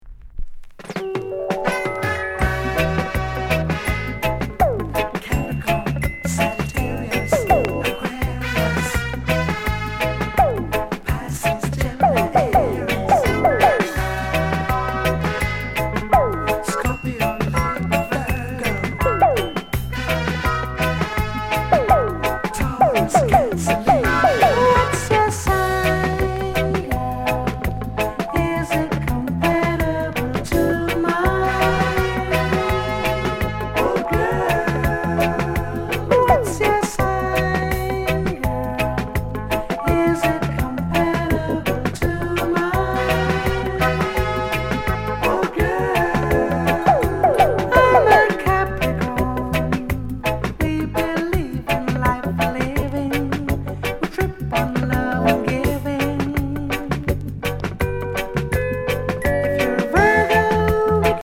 ��VG�� �٤��ʽ�����ޤ����ɹ������Ǥ��� Coment NICE LOVERS ROCK